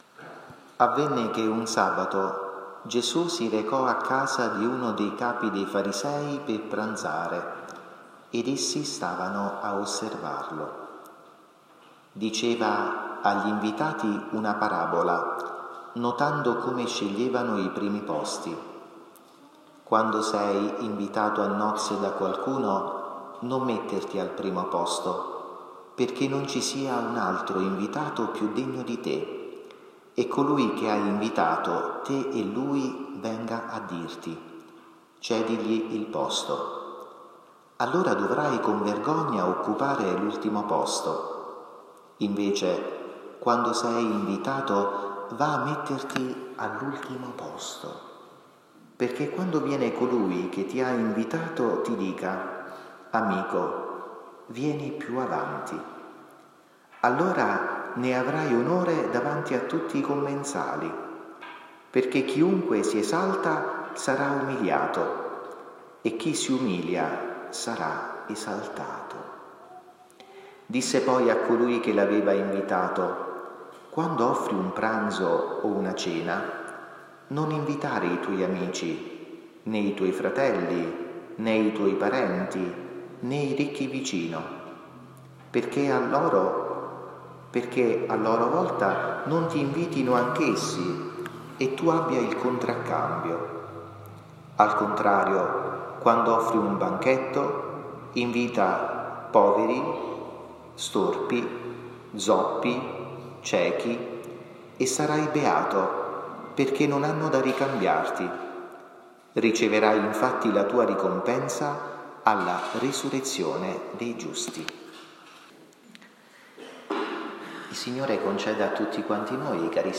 omelia-domenica-28-agosto-22.mp3